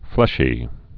(flĕshē)